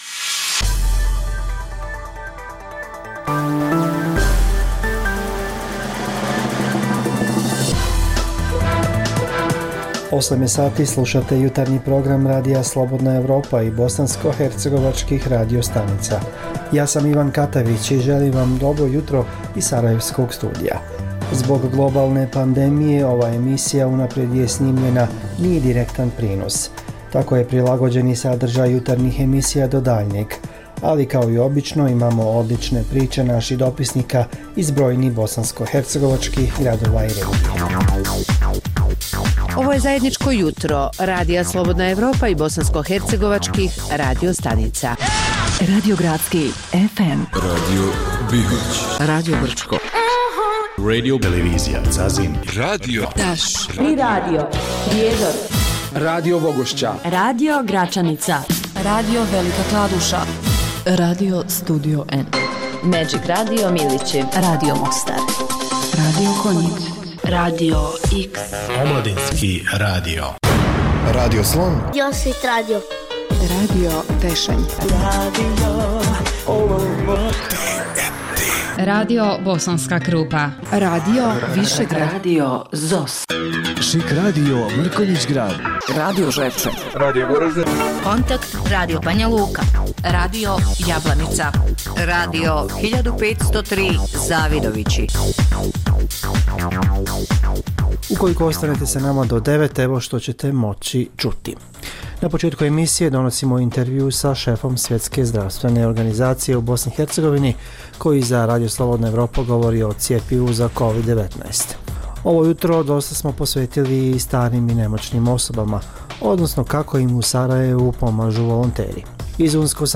Zbog pooštrenih mjera kretanja u cilju sprječavanja zaraze korona virusom, ovaj program je unaprijed snimljen. Reporteri iz cijele BiH javljaju o najaktuelnijim događajima u njihovim sredinama.